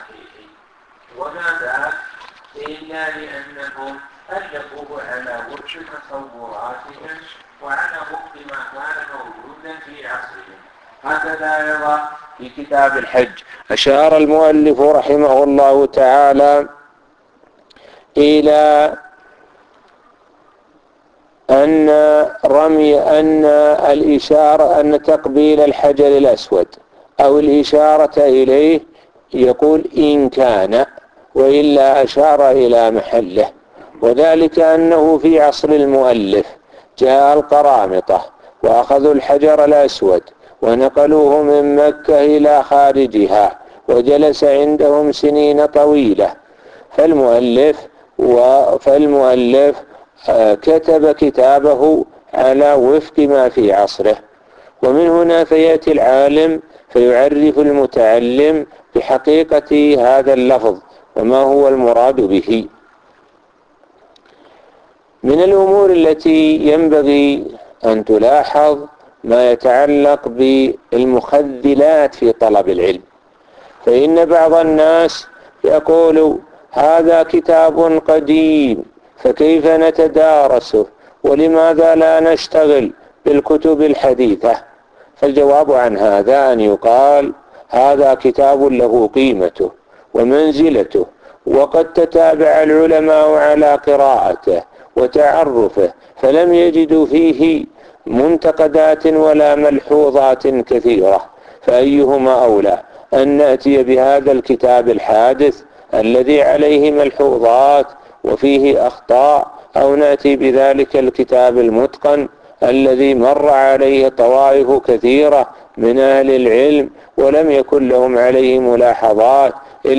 الدرس---1 مقدمة